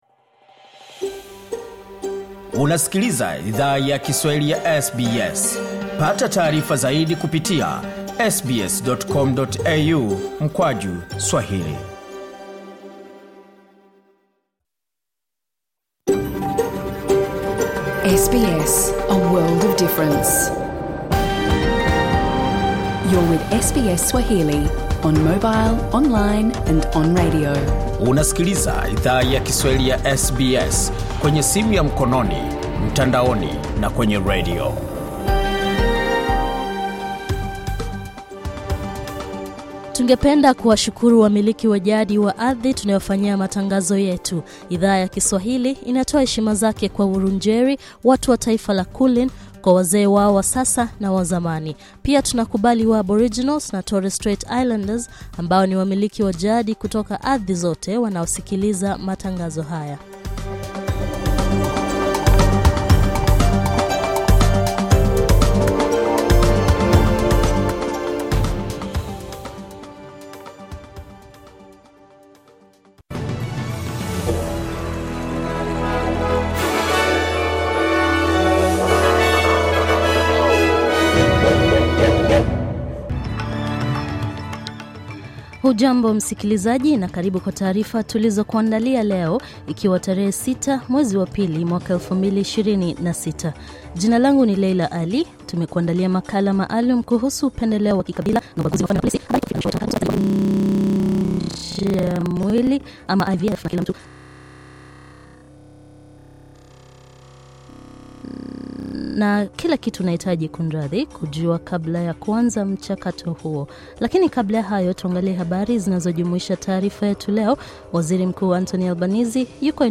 Taarifa ya habari:waziri Mkuu Anthony Albanese atasaini mkataba wa kihistoria wa usalama na Rais wa Indonesia